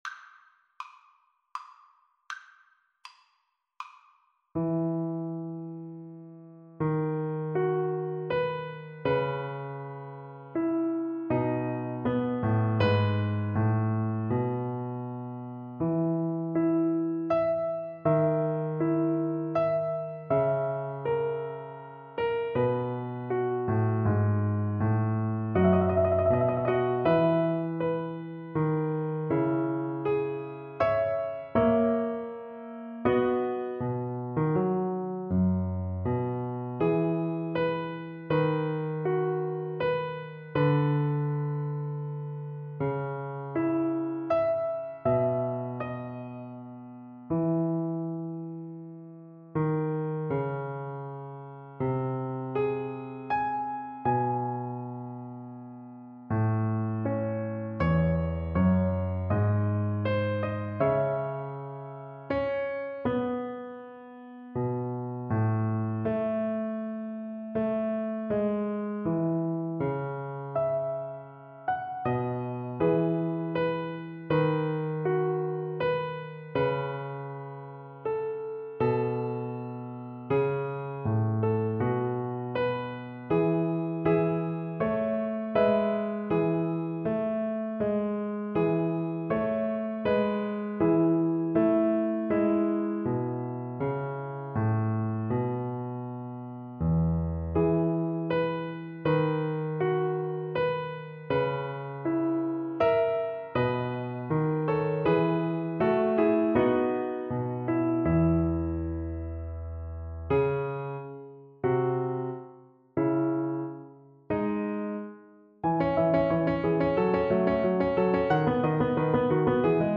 Play (or use space bar on your keyboard) Pause Music Playalong - Piano Accompaniment Playalong Band Accompaniment not yet available transpose reset tempo print settings full screen
3/8 (View more 3/8 Music)
E major (Sounding Pitch) (View more E major Music for Soprano Voice )
Andante = c.80
Classical (View more Classical Soprano Voice Music)